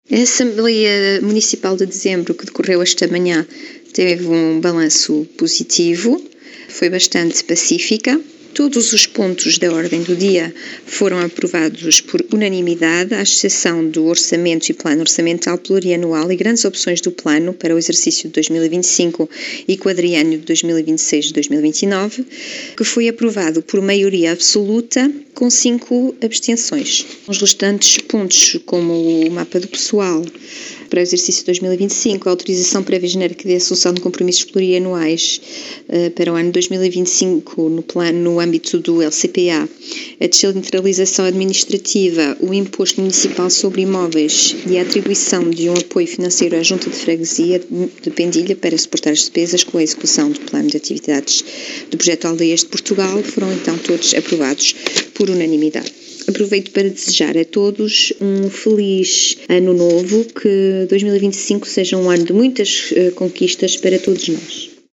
Cristina Pires, Presidente da Assembleia Municipal, em declarações à Alive FM, fez o resumo desta última sessão da AM do ano 2024, “um balanço positivo…”.